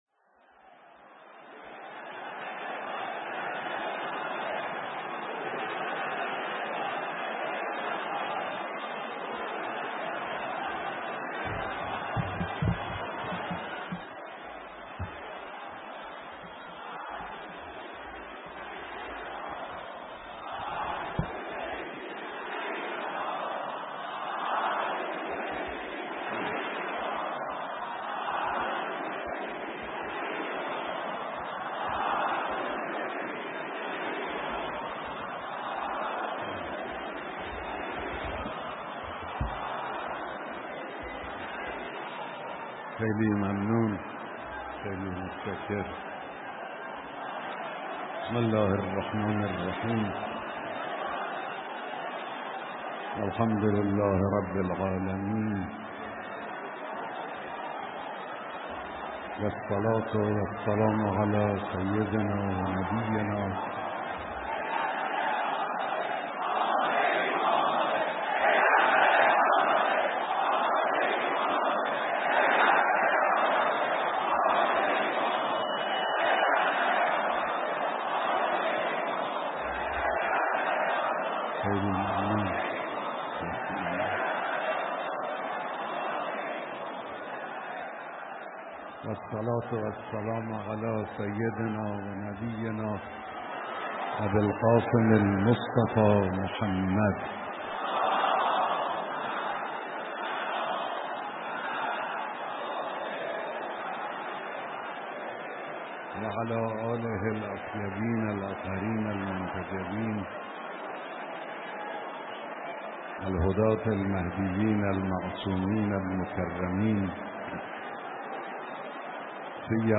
بیانات مقام معظم رهبری در اجتماع زائران و مجاوران حرم مطهر رضوی 98